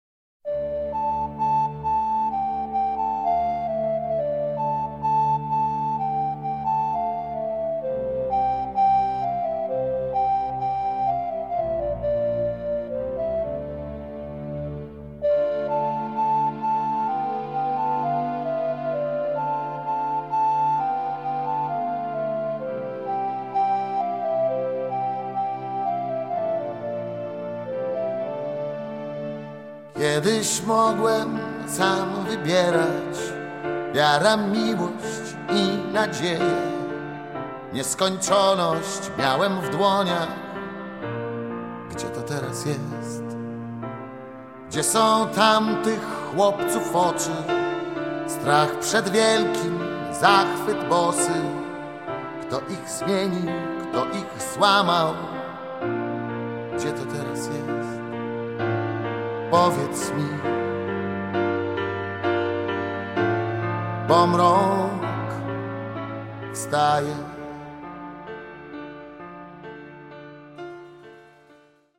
BAND DUET KEYB TEXT
VOC GUITAR KEYB BASS DRUMS TEKST